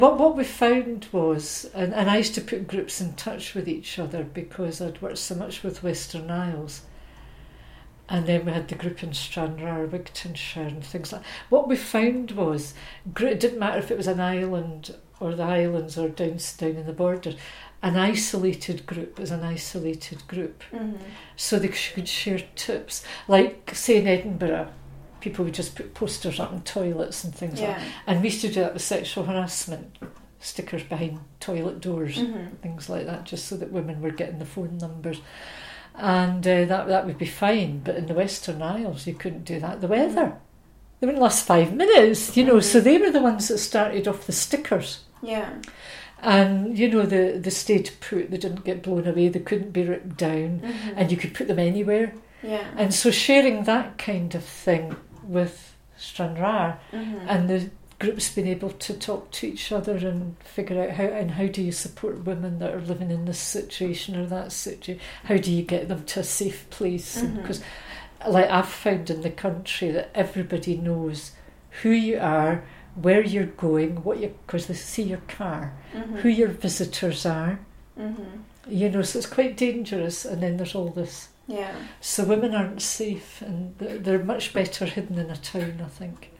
Speaking Out: Oral history clips
The Speaking Out project is gathering oral history interviews with women connected to the Women’s Aid movement, both past and present.